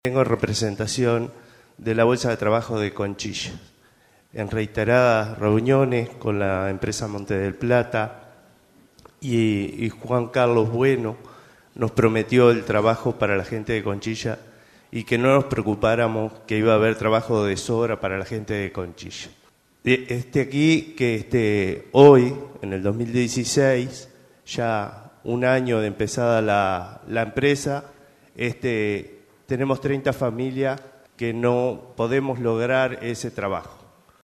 Un representante de la Bolsa de Trabajo de Conchillas planteó esta mañana en el Consejo de Ministros que se celebra en Carmelo, que la empresa Montes del Plata "prometió trabajo para todos" y que sin embargo hay 30 familias que están sin empleo.